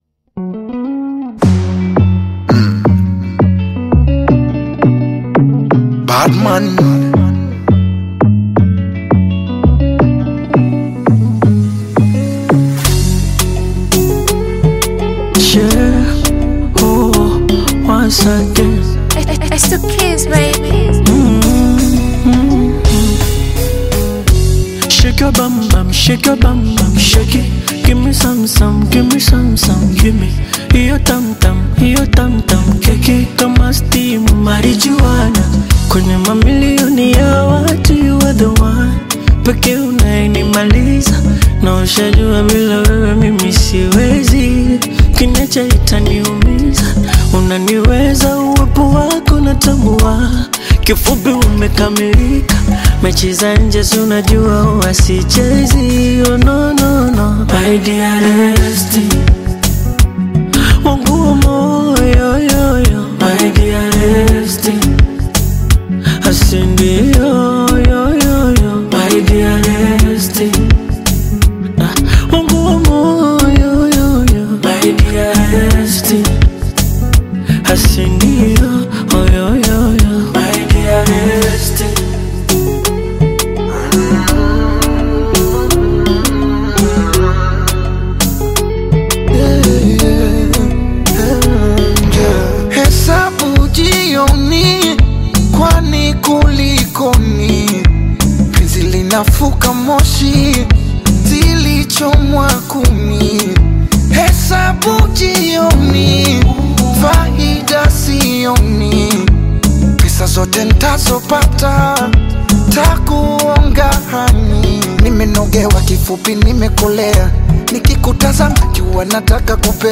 is a soulful Bongo Flava/Afro-Pop collaboration
emotive vocals
smooth delivery over lush rhythms
Genre: Bongo Flava